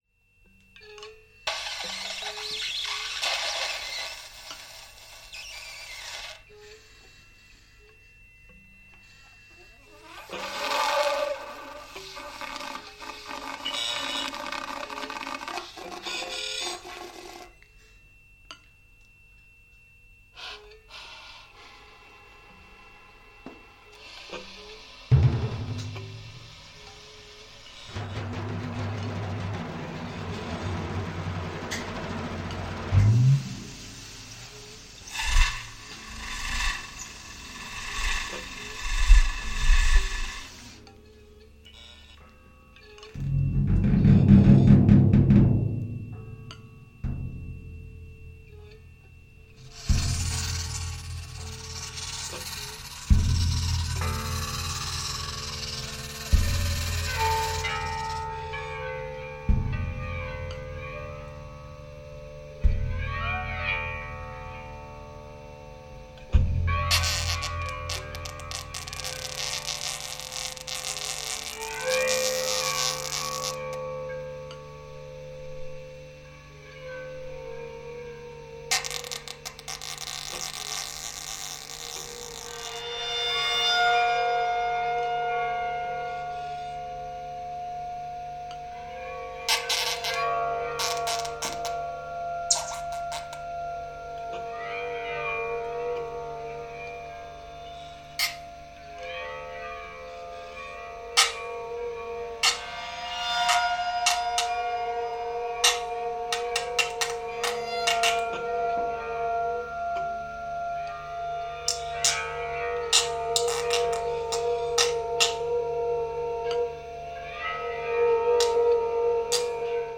percussion
reeds
guitar